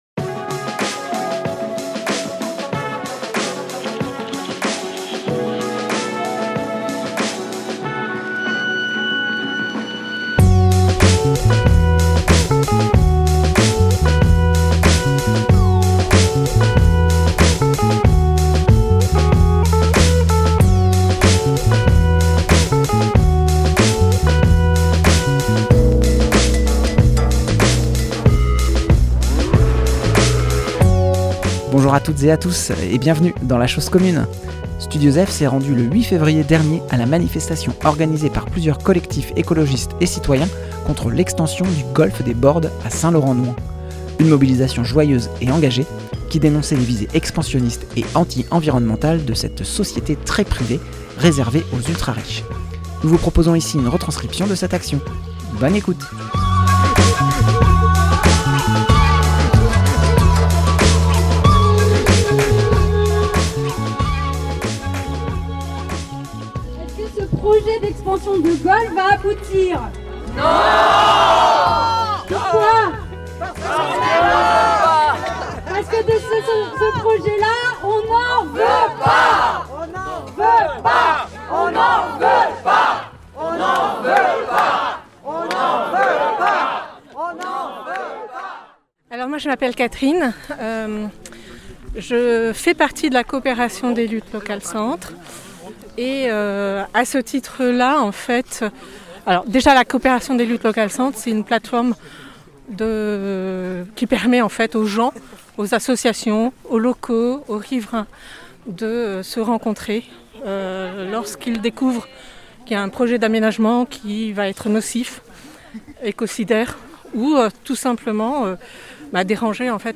Un reportage in situ, permettant d’entendre l’ambiance festive et engagée pour défendre le vivant et les espaces naturels protégés.
reportage-golf-des-Bordes.mp3